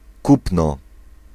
Ääntäminen
Ääntäminen France: IPA: /a.ʃa/ Haettu sana löytyi näillä lähdekielillä: ranska Käännös Ääninäyte Substantiivit 1. kupno {n} 2. zakup {m} Suku: m .